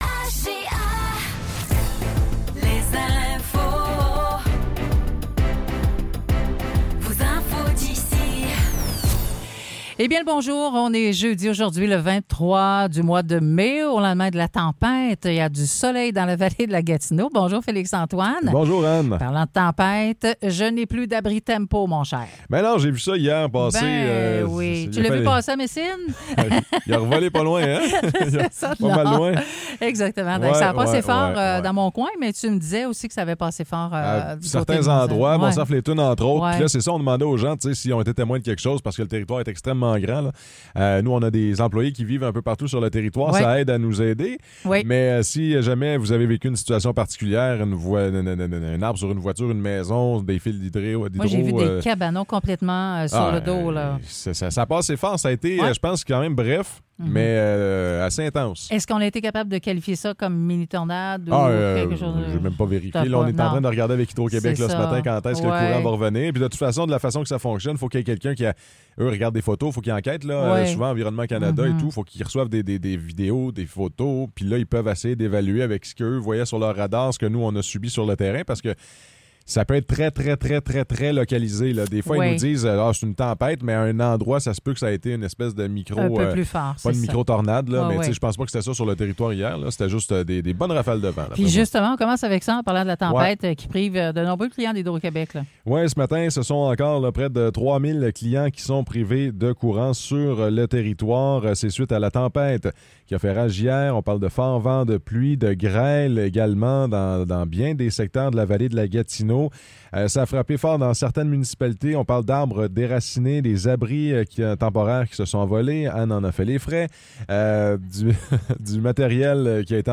Nouvelles locales - 23 mai 2024 - 9 h